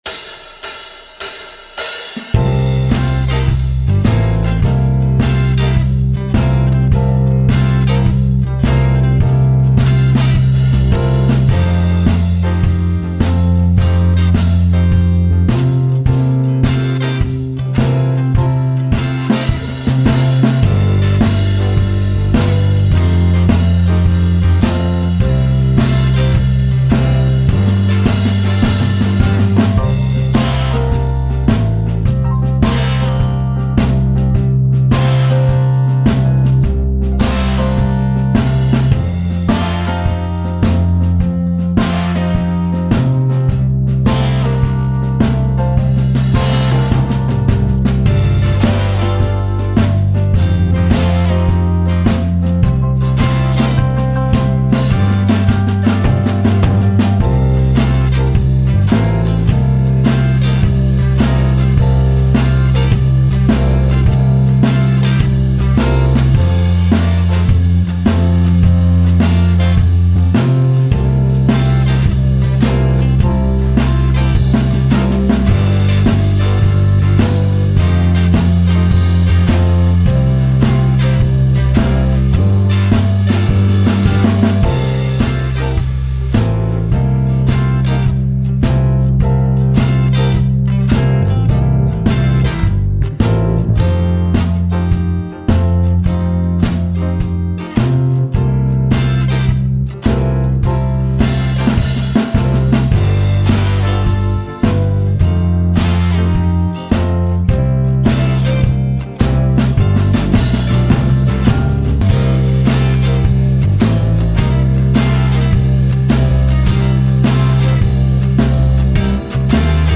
12 Bar Blues Backing track